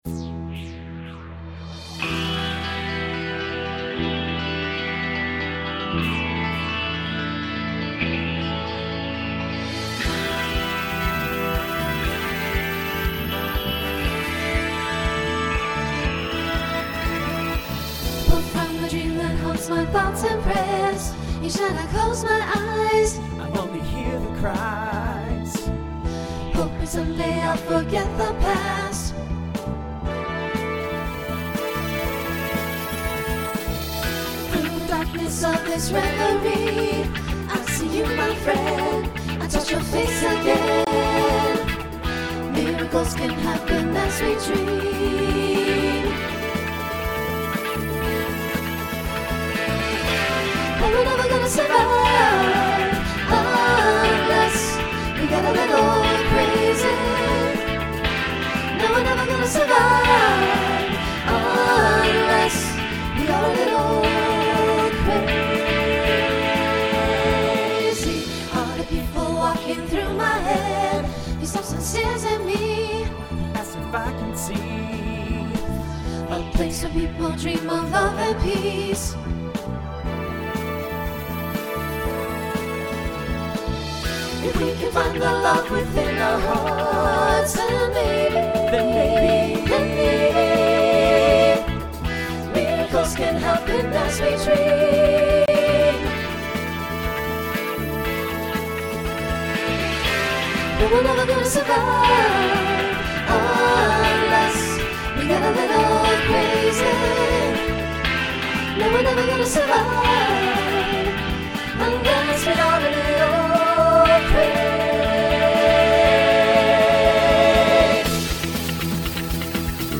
SATB Instrumental combo
Pop/Dance , Rock